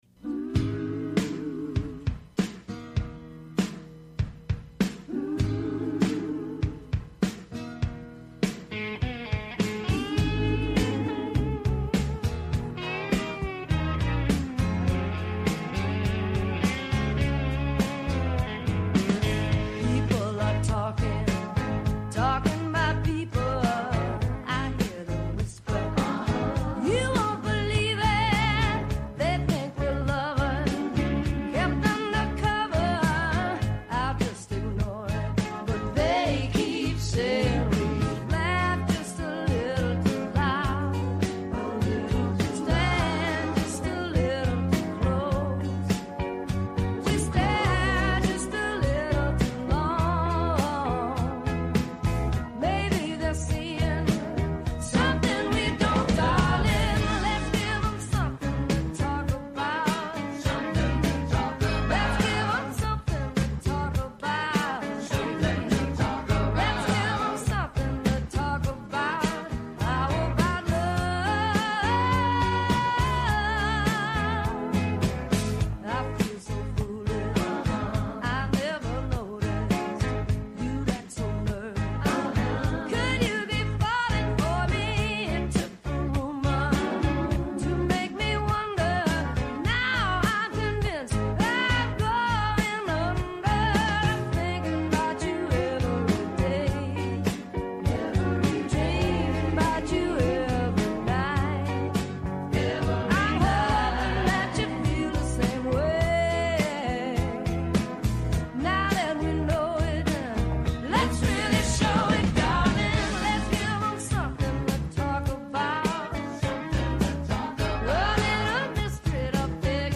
The show features interviews and discussion with political figures and newsmakers on a range of topics of importance to Columbia County, N.Y., and beyond.